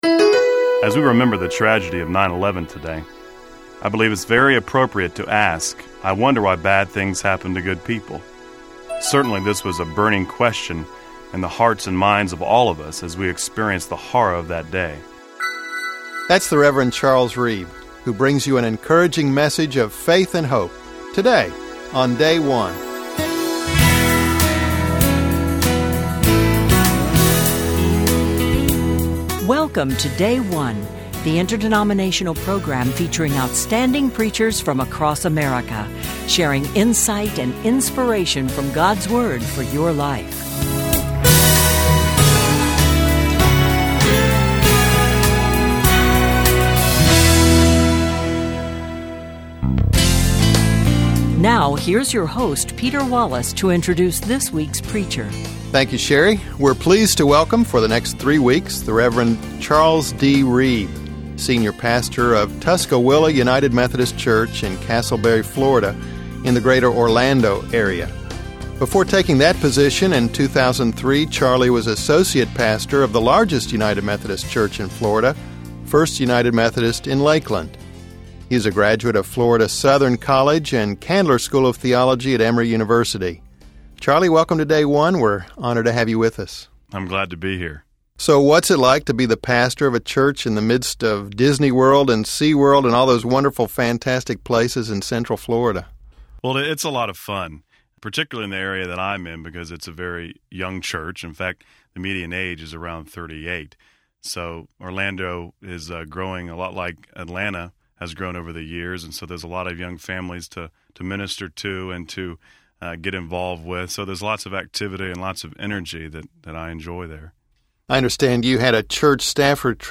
United Methodist Church The 17th Sunday after Pentecost Romans 8:28-39